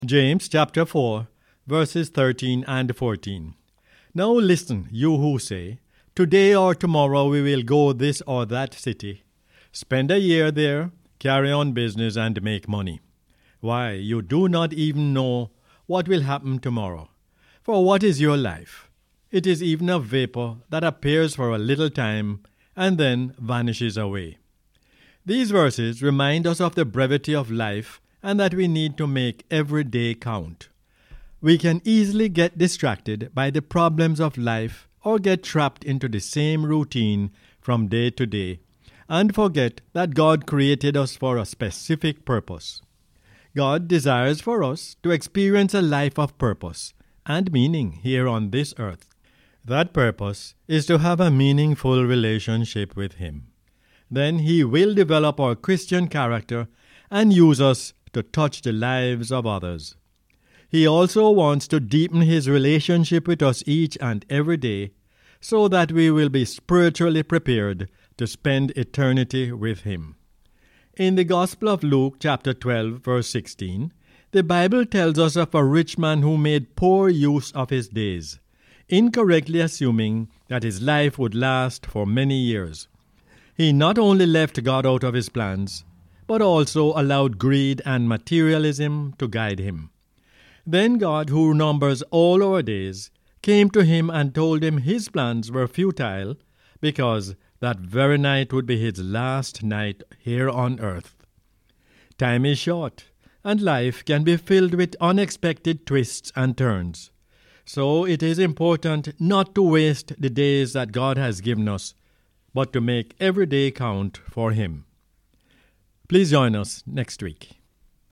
James 4:13-14 is the "Word For Jamaica" as aired on the radio on 6 November 2020.